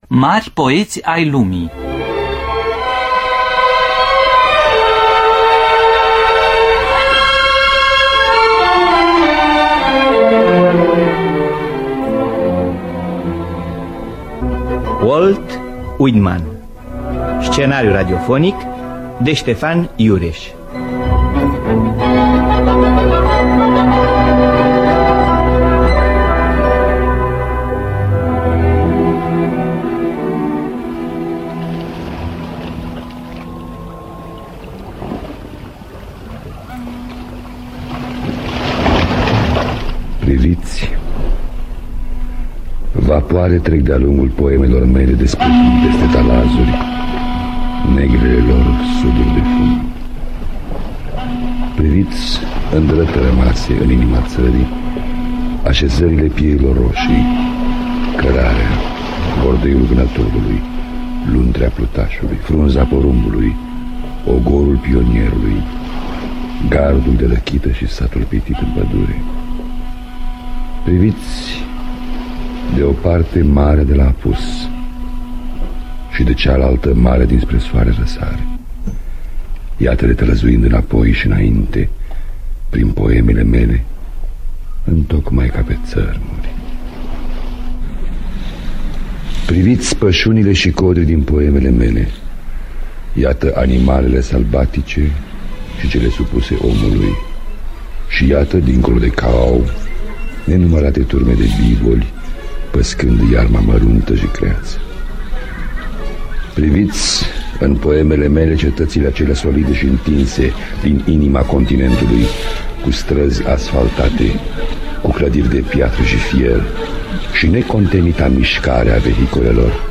Scenariu radiofonic de Ştefan Iureş.